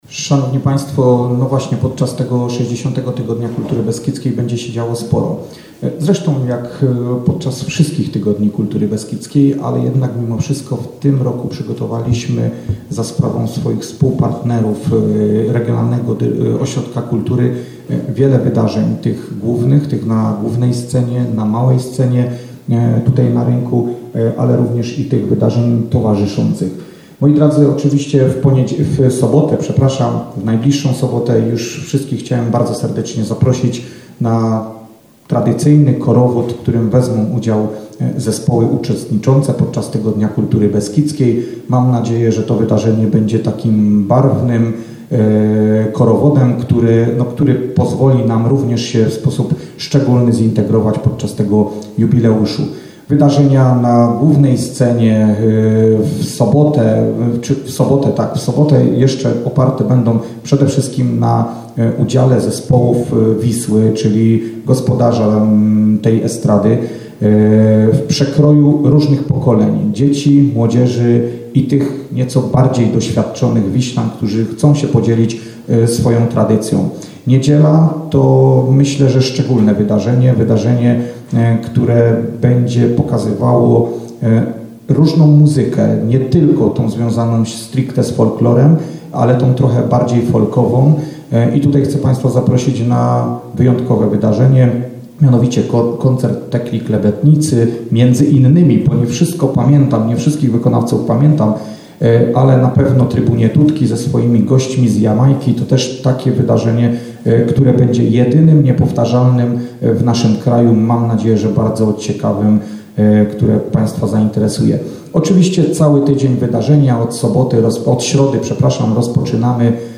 Reprezentanci Wisły, Szczyrku, Żywca, Oświęcimia, Makowa Podhalańskiego, Istebnej, Ujsół i Jabłonkowa na Zaolziu wystąpili dzisiaj podczas konferencji prasowej w Miejskiej Bibliotece Publicznej w Wiśle.
O wiślańskich atrakcjach mówił burmistrz Tomasz Bujok.